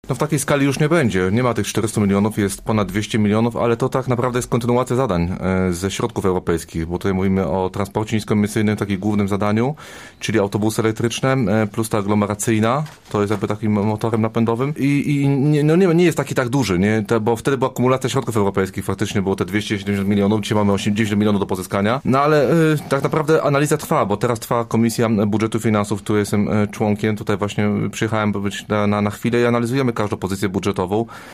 Marcin Pabierowski, przewodniczący klubu radnych Koalicja Obywatelska, mówił, że 200 mln zł, to połowa środków, które na inwestycje była zapisana w tegorocznym budżecie: